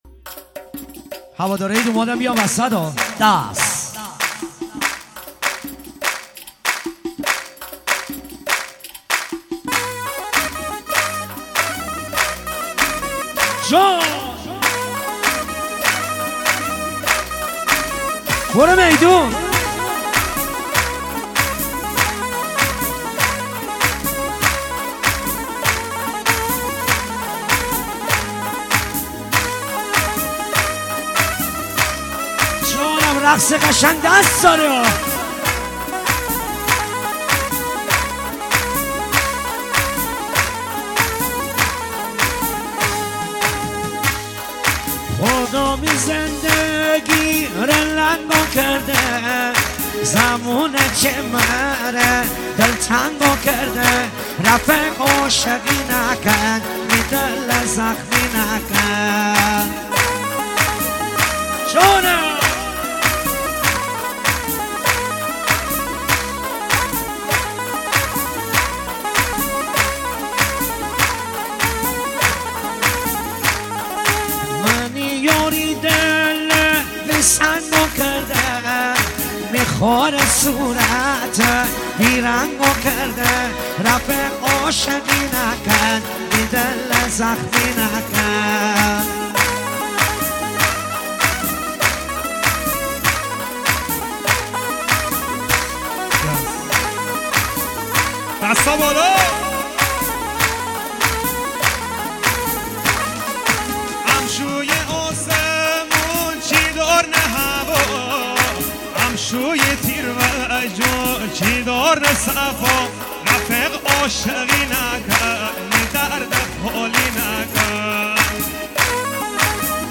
آهنگ جدید مازندرانی
آهنگ شاد